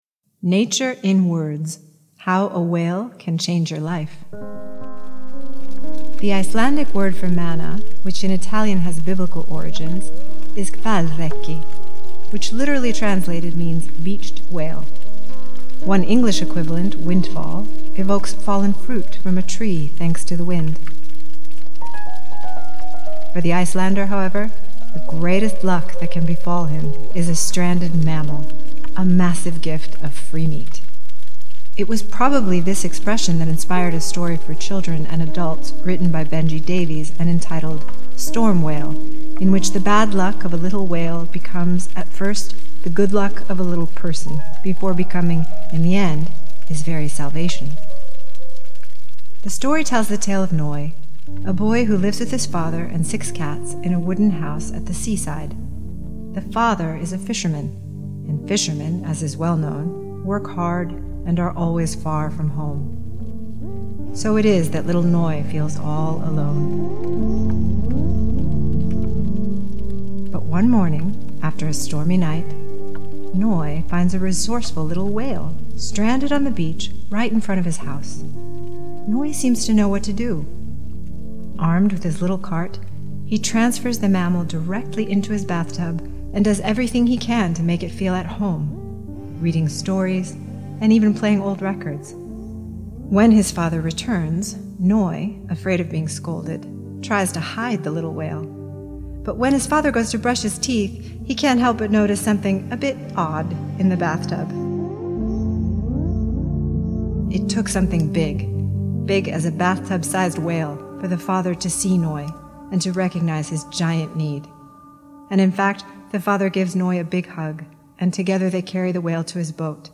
English version - Translated and narrated
Sound design